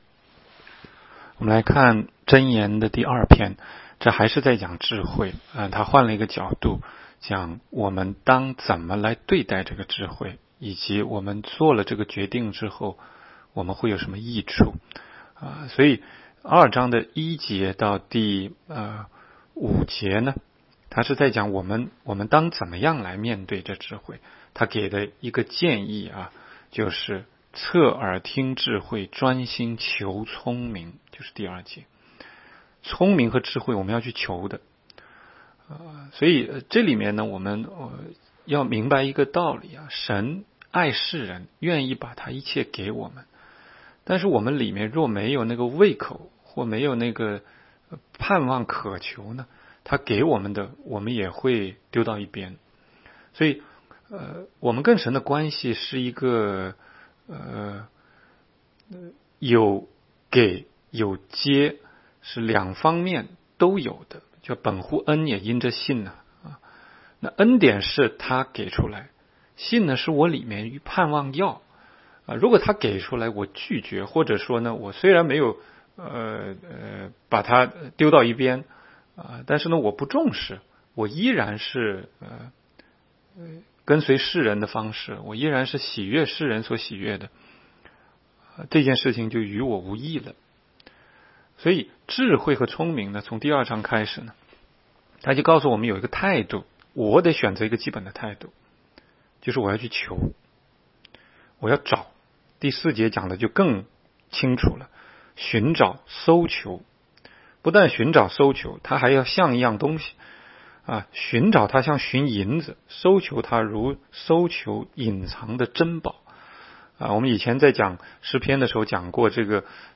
16街讲道录音 - 每日读经 -《 箴言》2章